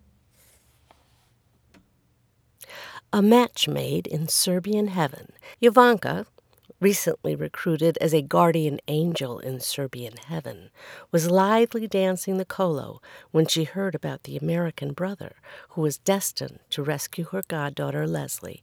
I got through the zoom manual, shut myself up in my closet, the one with egg crate foam hanging from the ceiling, and recorded a sample.
There’s still a tiny bit of motor noise in there.